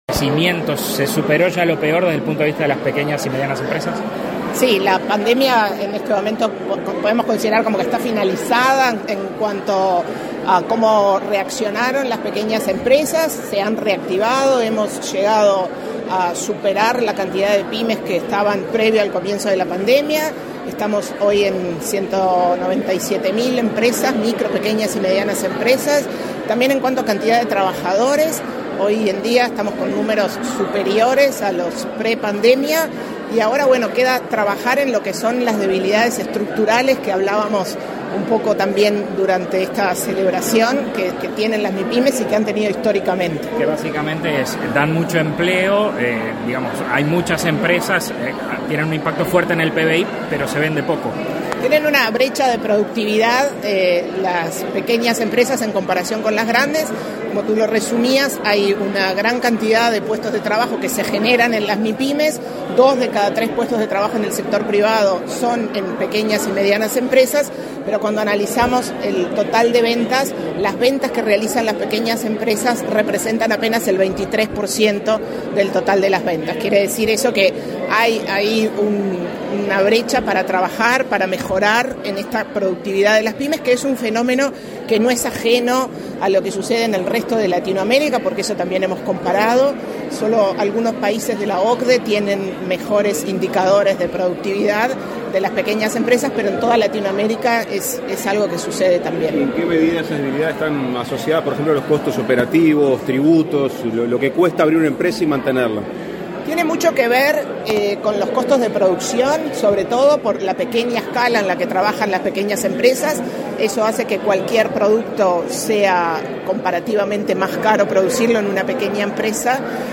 Declaraciones a la prensa de la presidenta de ANDE, Carmen Sánchez
Tras el evento, Sánchez efectuó declaraciones a la prensa.